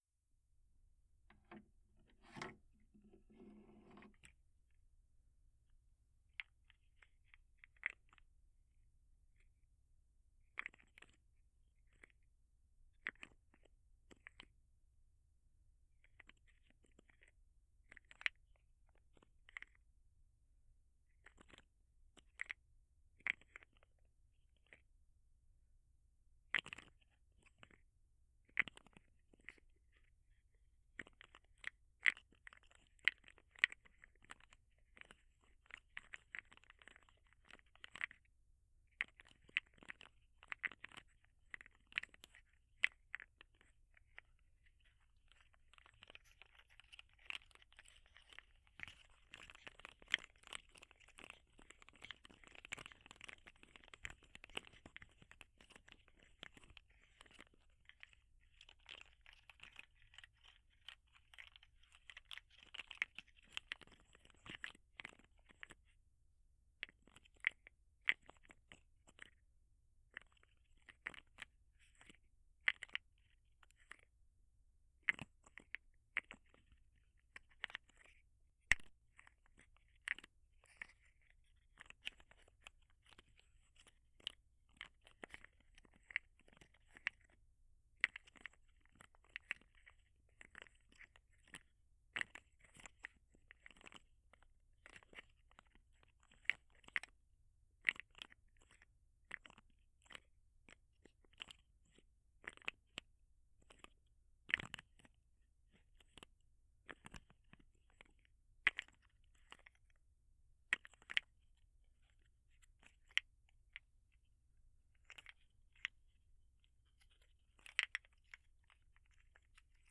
03.生活音パートのみ.wav